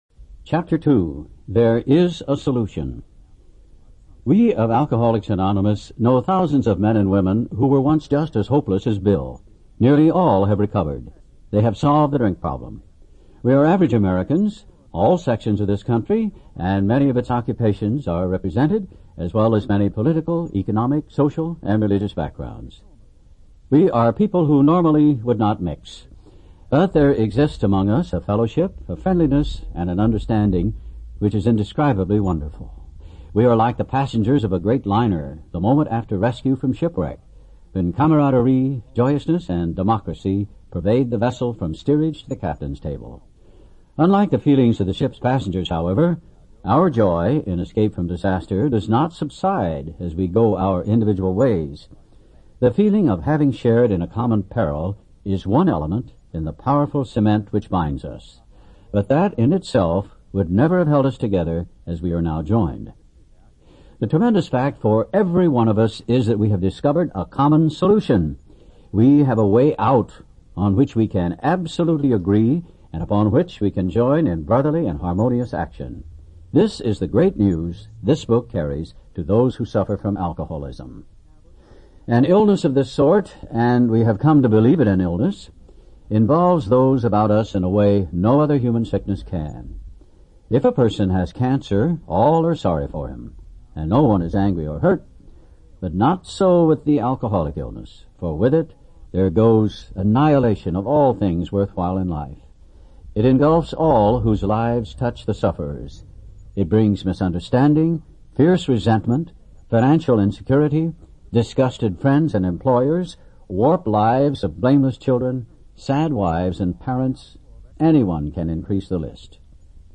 AA Audio Books | Big Book, 12 & 12, A New Pair of Glasses